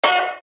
crash.mp3